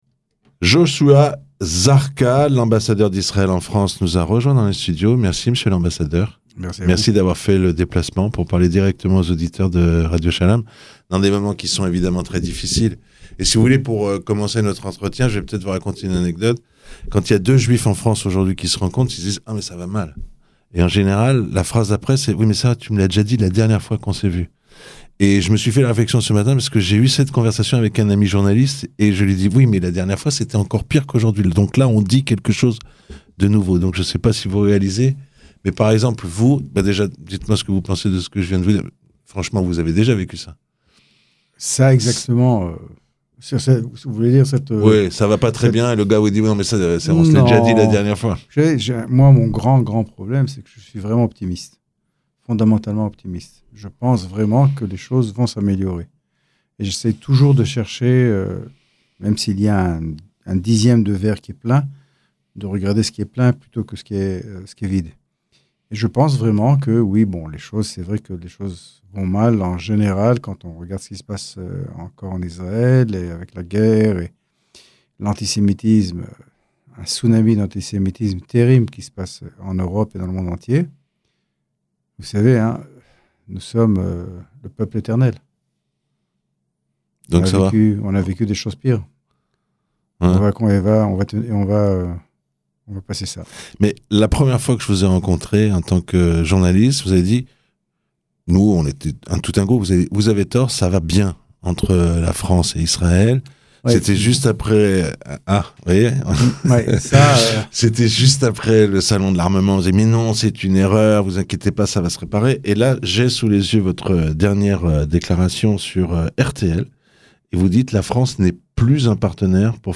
Joshua Zarka, ambassadeur d’Israël en France était l'invité de la rédaction dde Radio Shalom à l'occasion de la tenue d'une conférence co-présidée par la France et l'Arabie Saoudite pour la reconnaissance d'un Etat palestinien;